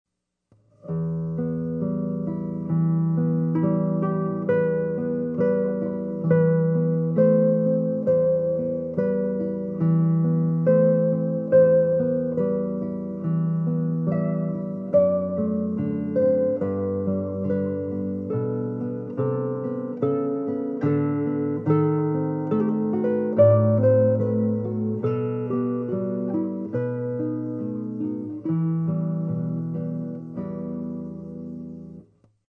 A seguir são disponibilizados alguns pequenos arquivos MP3 demonstrando a sonoridade original e a sonoridade após a equalização.
Violão sem Caixa de Ressonância
Música (com eq.)
Obs: A duas primeiras amostras do violão sem caixa de ressonância foram obtidas com um Violão Miranda Modelo CFX-200.